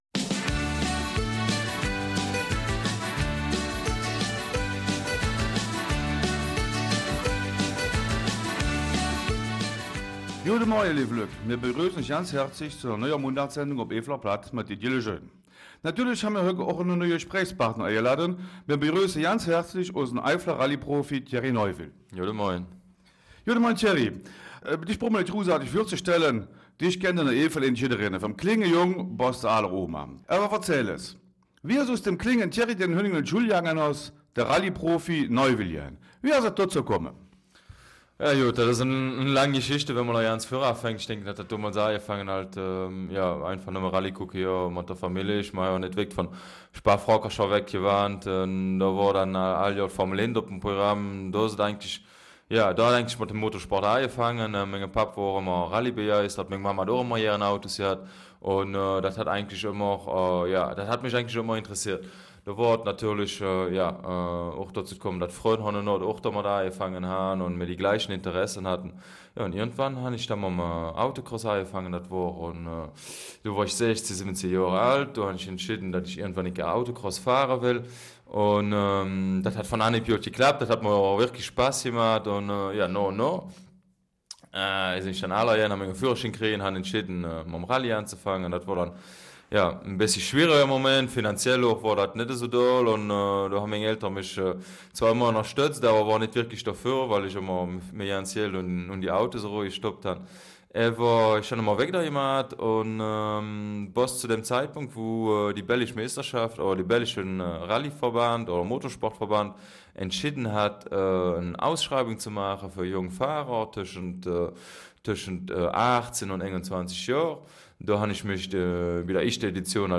Eifeler Mundart
interviewt Thierry Neuville
Zu hören ist das Interview, das bei einem Besuch von Thierry Neuville in seiner ostbelgischen Heimat aufgezeichnet wurde, pünktlich zur Rallye Schweden, für die viele Motorsportfans dem gebürtigen St. Vither wieder die Daumen drücken.